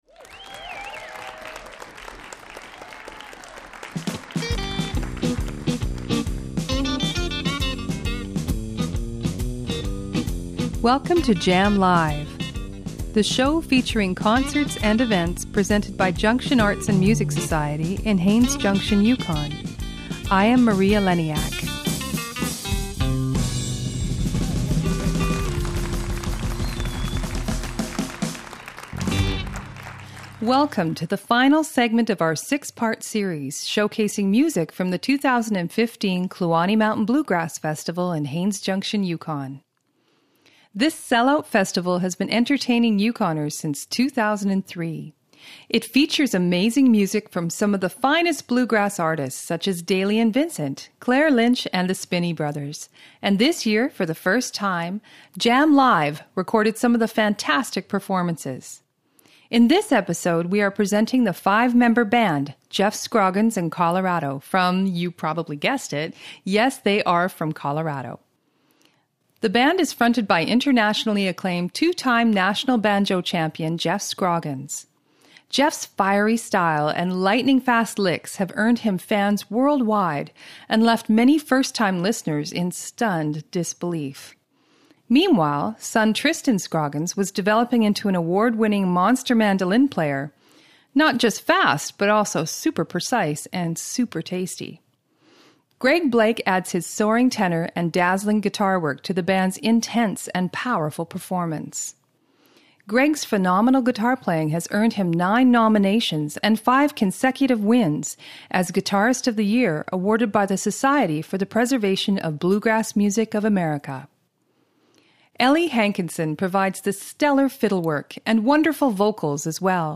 Live music recorded in Haines Junction, Yukon.